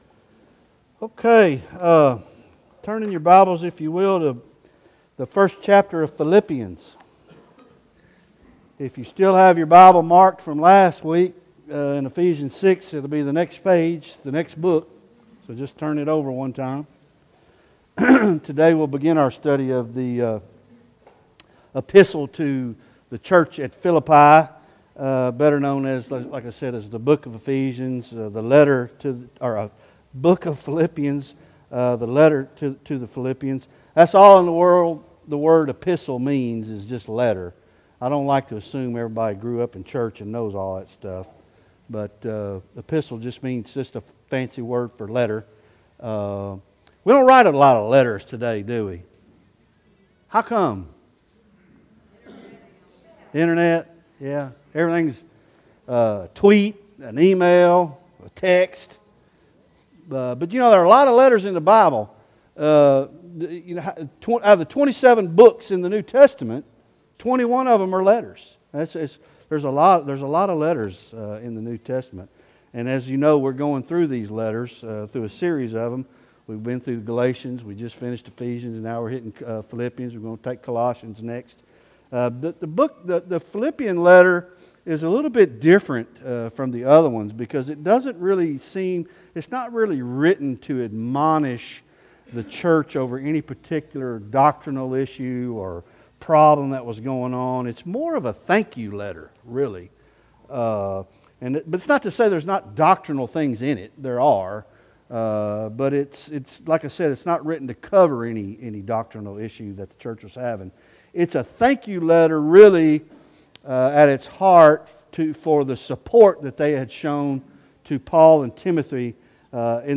Sunday School Philippians 1 – Part I